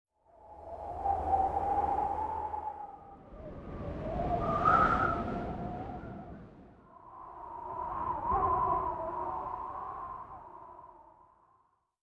CHQ_FACT_whistling_wind.mp3